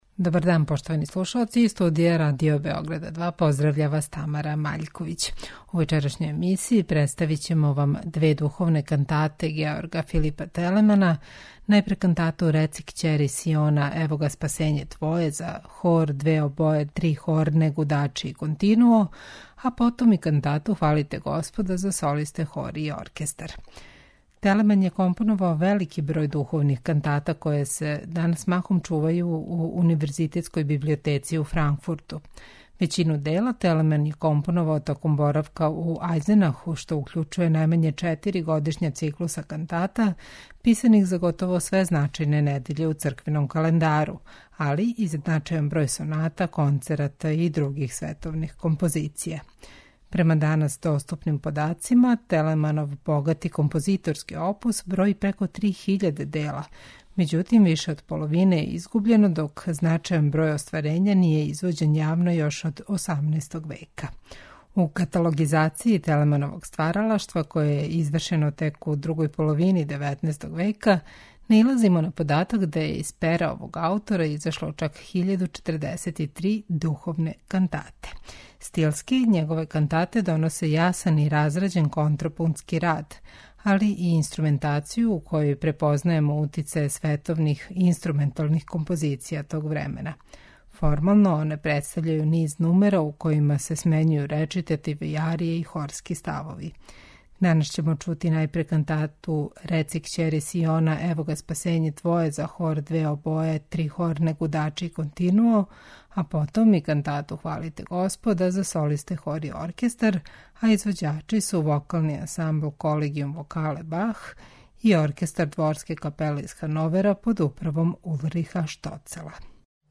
Духовне кантате Георга Филипа Телемана
Стилски, Телеманове кантате доносе јасан и разрађен контрапунктски рад, али и инструментацију у којој препознајемо утицаје световних инструменталних композиција тог времена.
вокални ансамбл